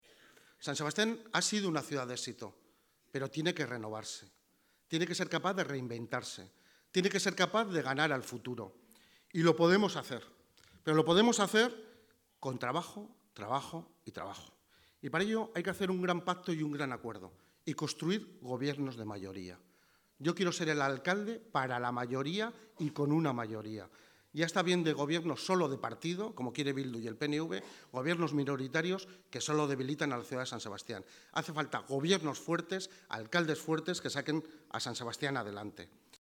El candidato a la alcaldía de San Sebastián, Ernesto Gasco ha reclamado esta mañana en el mitin central celebrado en el Kursaal, gobiernos fuertes y alcaldes fuertes para la próxima legislatura para sacar a la ciudad de San Sebastián de la parálisis a la que han abocado el gobierno de Bildu y el PNV que le ha permitido gobernar a la coalición soberanista en minoría.